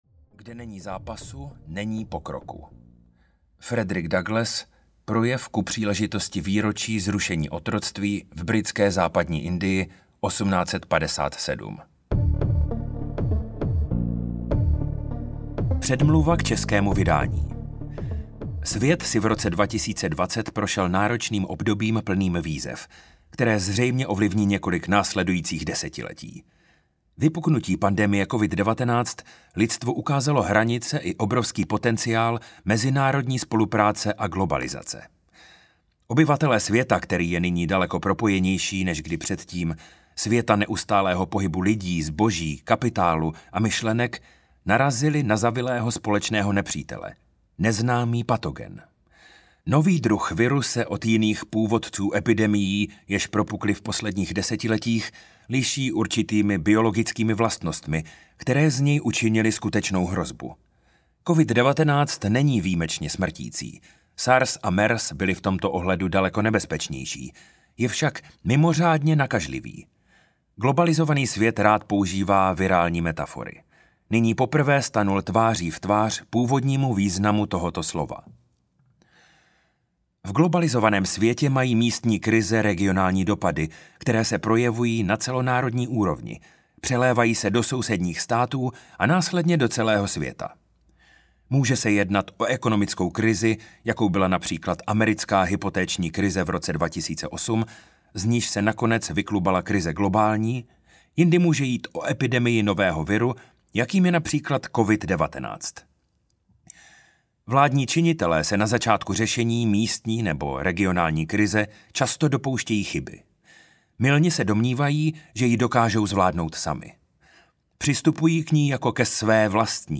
Vzpoura proti globalizaci audiokniha
Ukázka z knihy